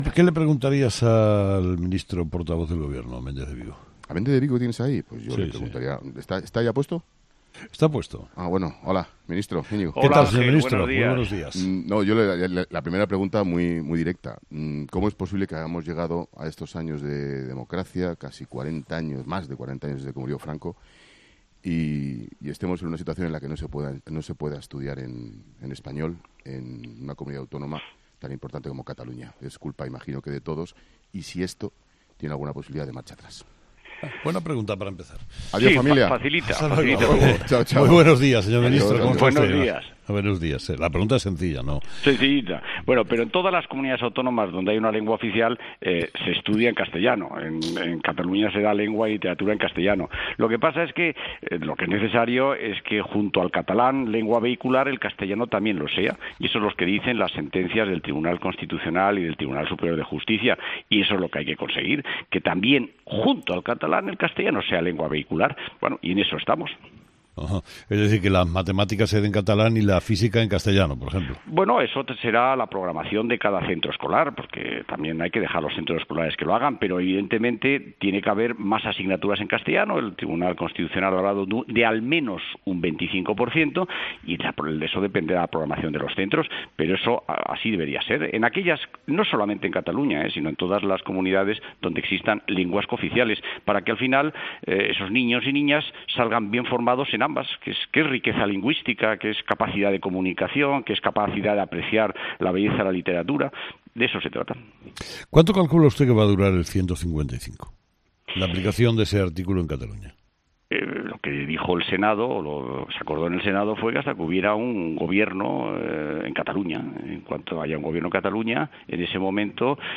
Entrevista al ministro de Educación íñigo Méndez de Vigo